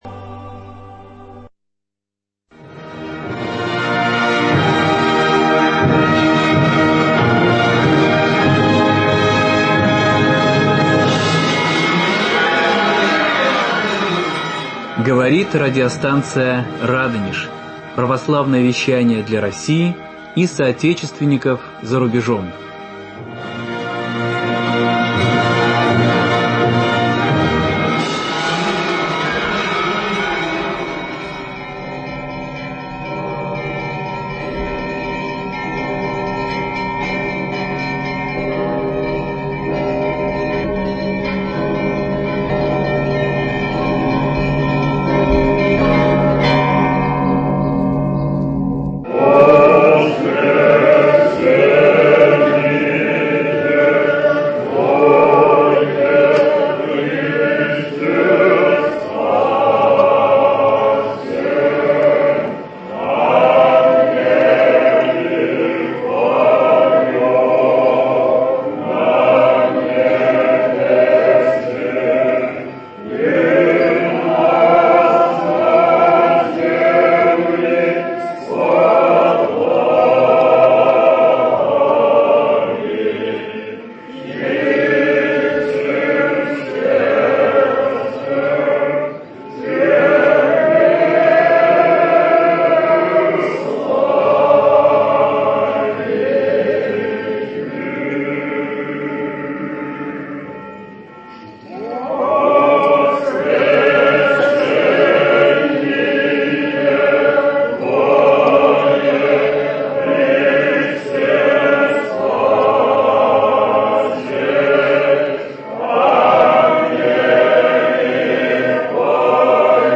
В воскресенье, в первый день Пасхи Христовой, состоялась наша праздничная беседа, посвященная Празднику Праздников и Торжеству из Торжеств